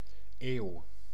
Ääntäminen
Synonyymit tijdperk Ääntäminen Tuntematon aksentti: IPA: /eːu̯/ Haettu sana löytyi näillä lähdekielillä: hollanti Käännös 1. centuria {f} 2. siglo {m} Suku: f .